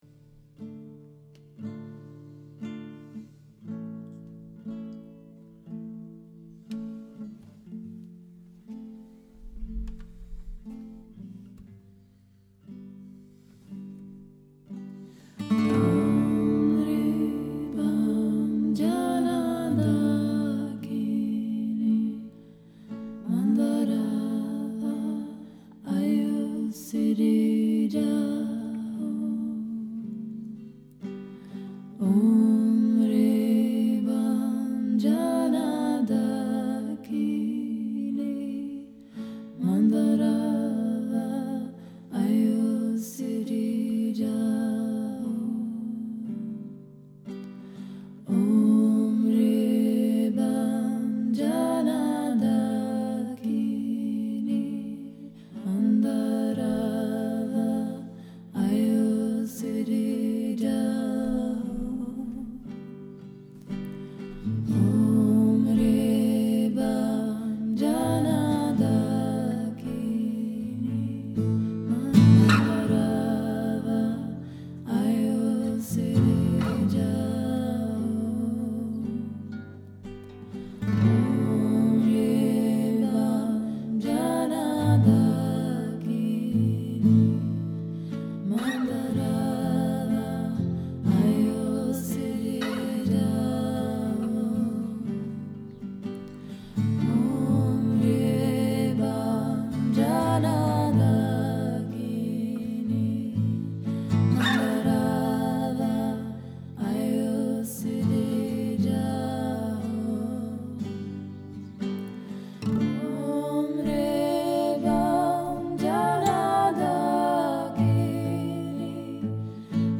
guitar and bass